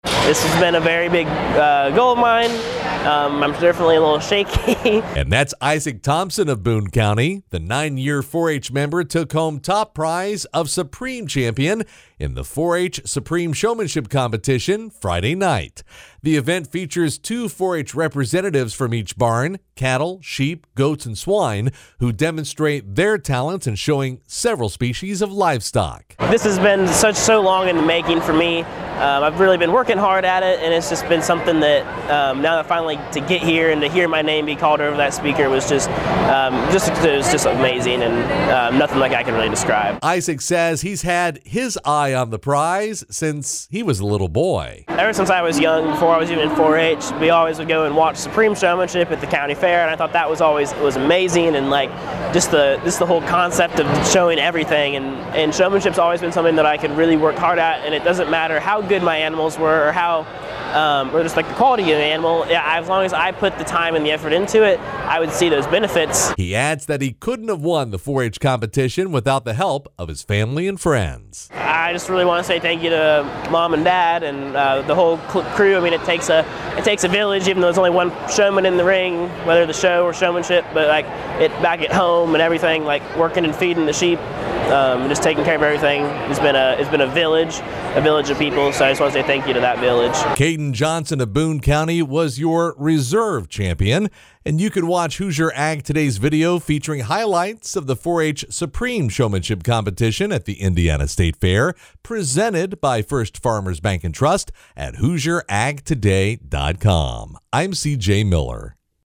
radio news report